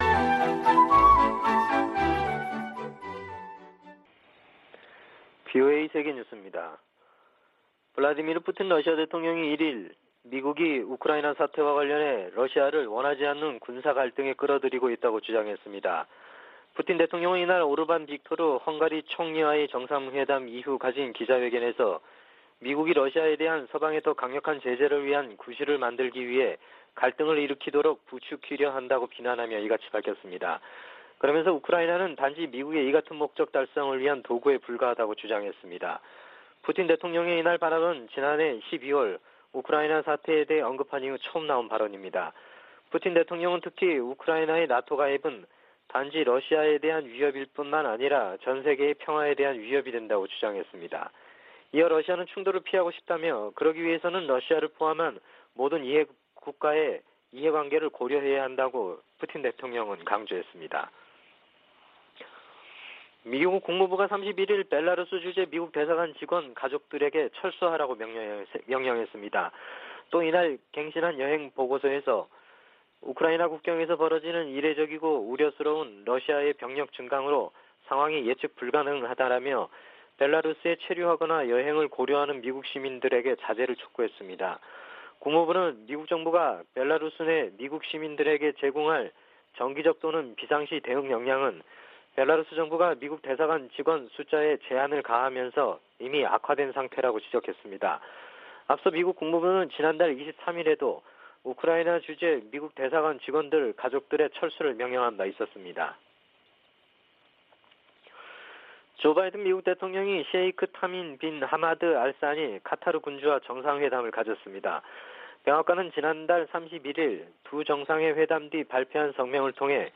VOA 한국어 아침 뉴스 프로그램 '워싱턴 뉴스 광장' 2021년 2월 2일 방송입니다. 미 국무부는 북한의 거듭된 미사일 발사에 대해 책임을 묻기 위한 다른 조치들을 추진 중이라고 밝혔습니다. 미 국방부는 북한의 미사일 도발 중단과 안보리 결의 준수를 바란다고 밝혔습니다.